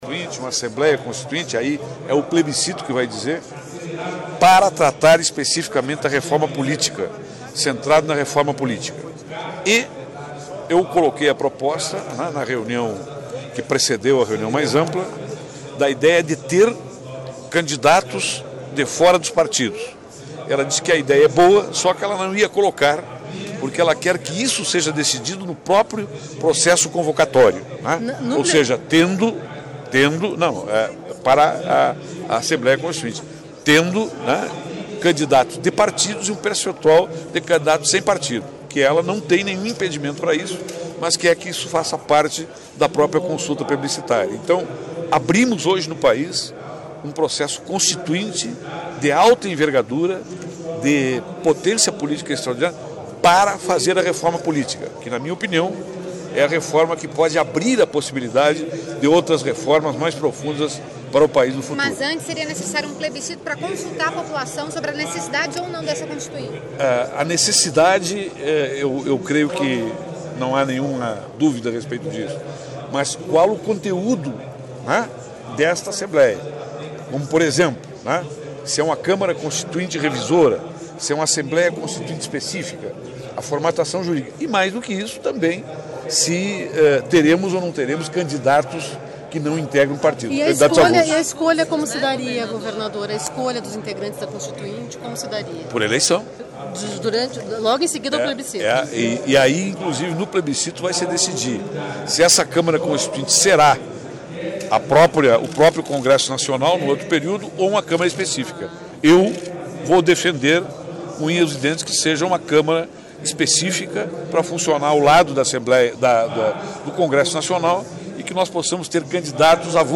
Entrevista do governador Tarso Genro sobre a instalação de uma Constituinte no país
Ouça na íntegra a entrevista do governador Tarso Genro, em Brasília, nesta segunda-feira (24).Local: Duração: 00:03:33